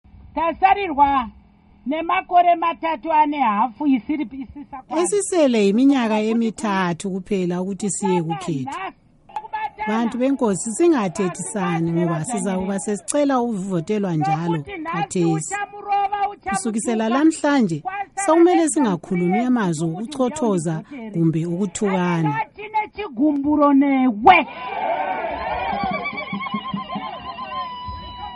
Embed share Amazwi kaNkosikazi Joice Mujuru by VOA Embed share The code has been copied to your clipboard.